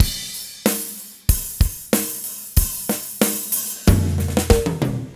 07 rhdrm93roll.wav